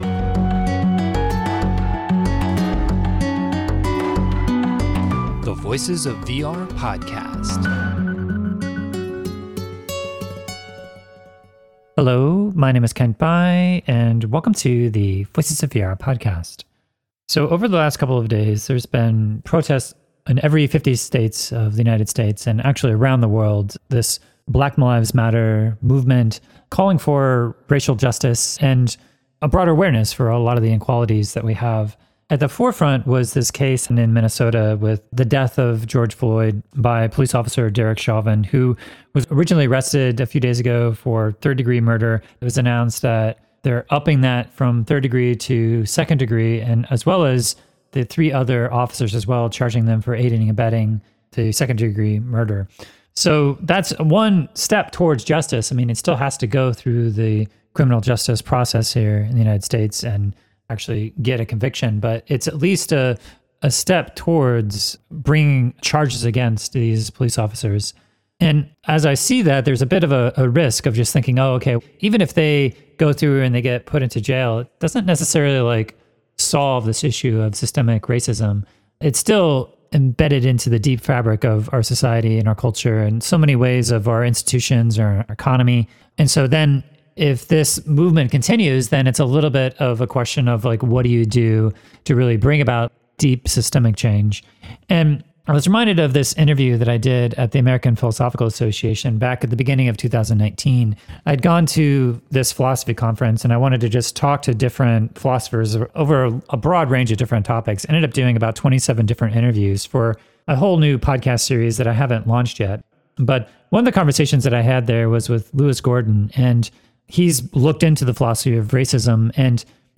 I had a conversation with philosopher Lewis Gordon at the American Philosophical Association Eastern Meeting in January 2019 that really stuck with me.